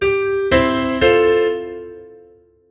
Together they form a chord, as if the melody is not anymore just one person, but a few people playing together.
All together the notes would sound like this:
eyck_example-vier-akkoorden.wav